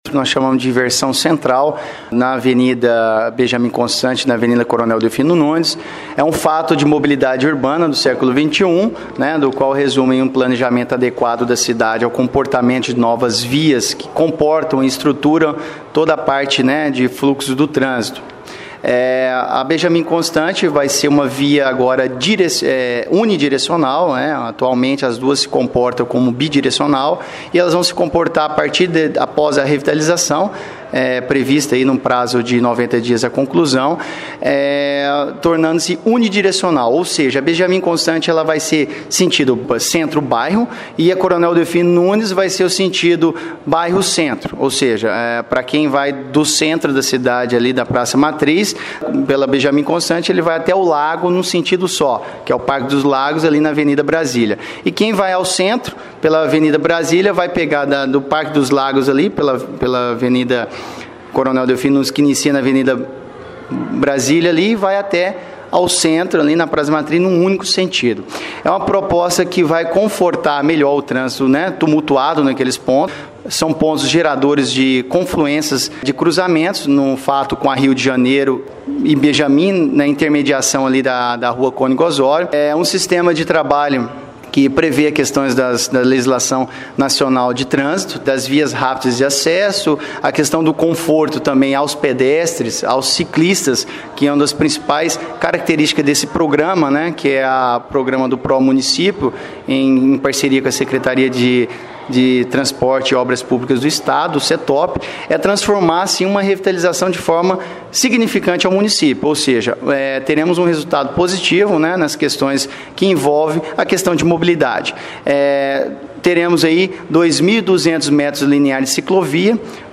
O Secretário João de Deus Braga Júnior, da Secretaria Municipal de Planejamento e Desenvolvimento Urbano, fala a respeito destas obras e quais serão as mudanças que ocorrerão no trânsito.